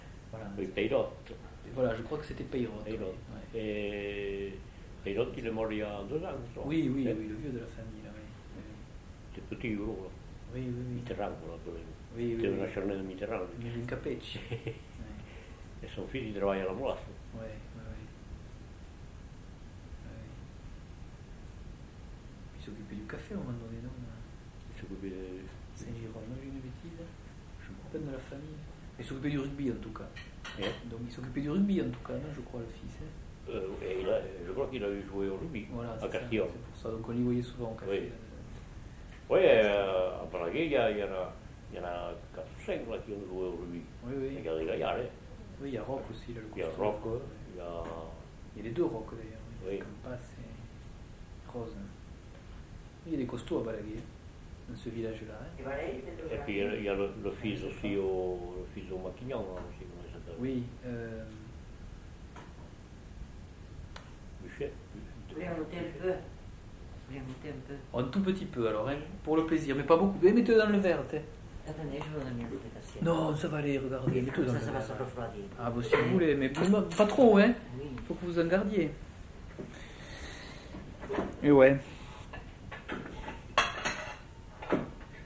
Aire culturelle : Couserans
Lieu : Pouech de Luzenac (lieu-dit)
Genre : parole